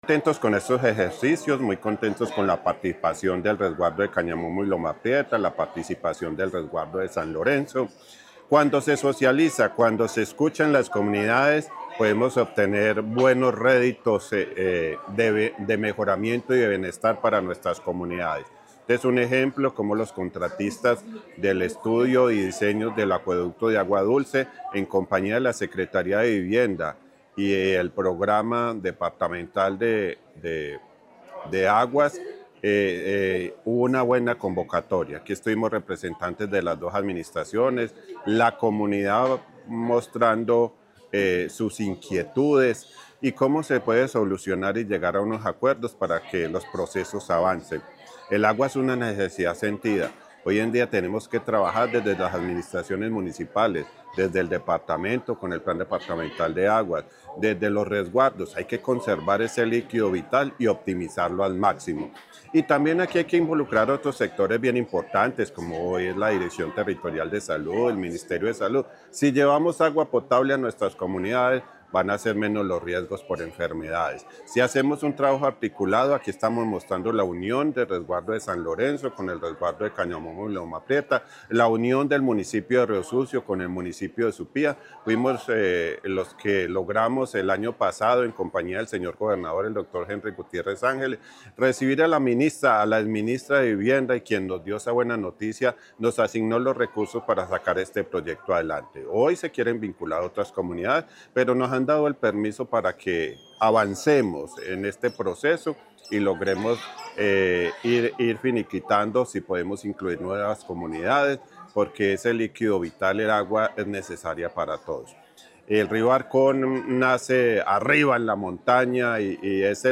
Alcalde de Supía, Héctor Mauricio Torres Álvarez.